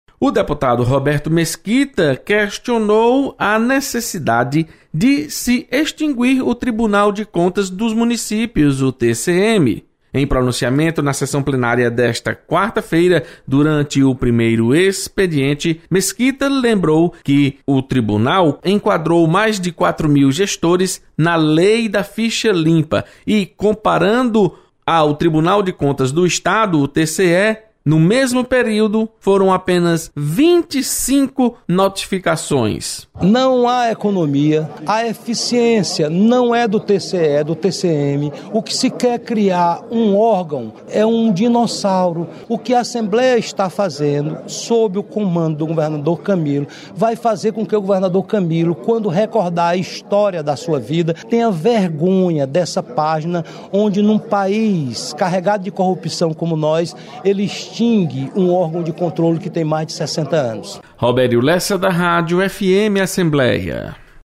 Você está aqui: Início Comunicação Rádio FM Assembleia Notícias Plenário